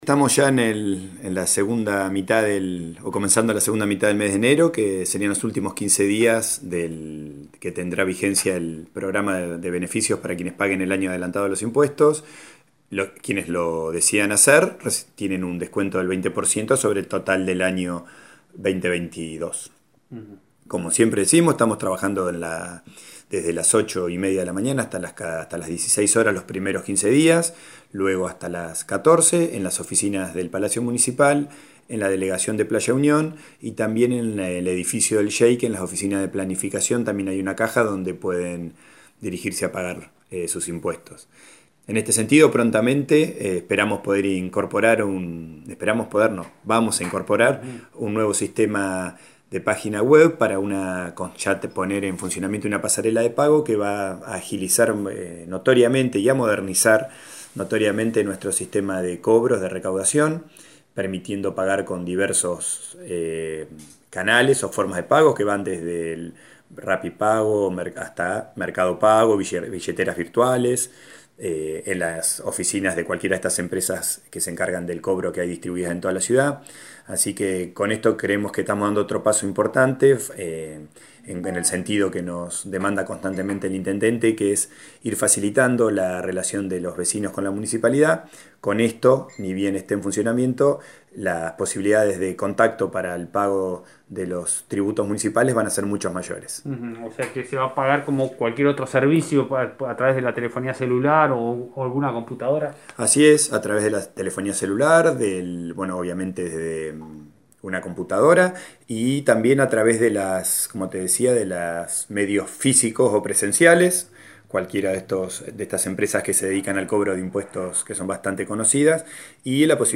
Lo informó Martín Sternet, secretario de Hacienda del Municipio de Rawson, en diálogo con FM Bahía Engaño.